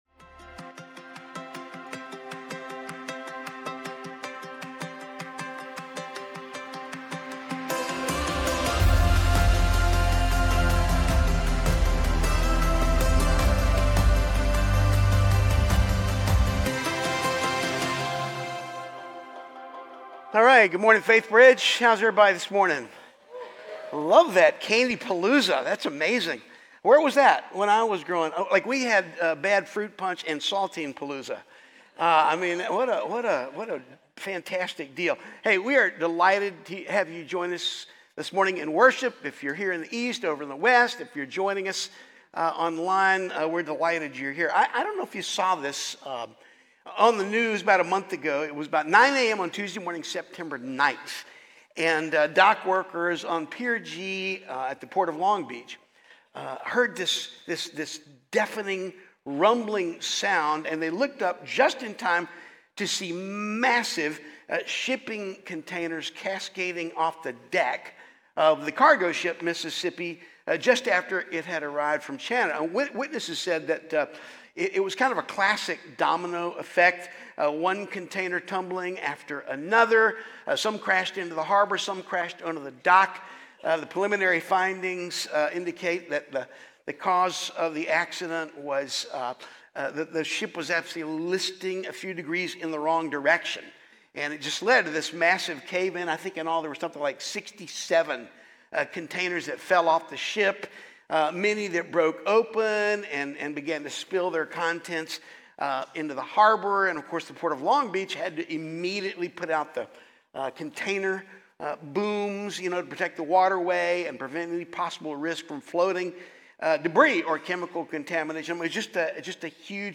Faithbridge Sermons When It All Blows Up in Your Face Oct 12 2025 | 00:38:25 1x 00:00 / 00:38:25 Subscribe Share Apple Podcasts Spotify Overcast RSS Feed Share Link Embed